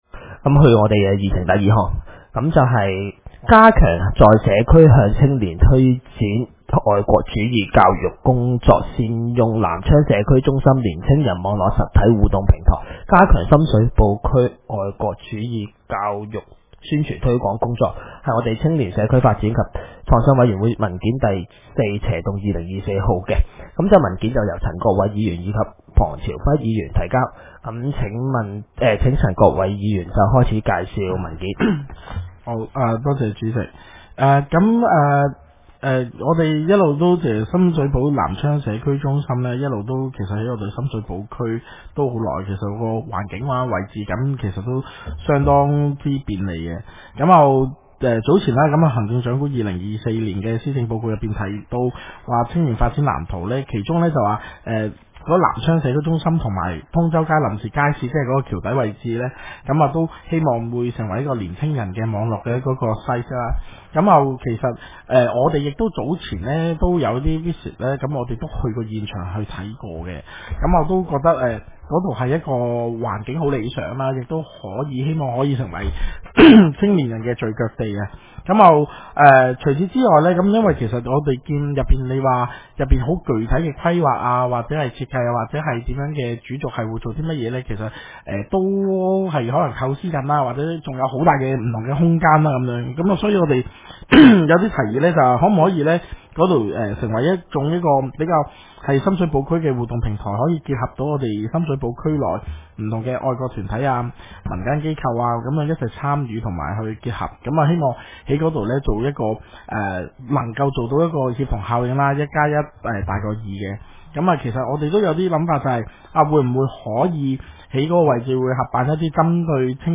委員會會議的錄音記錄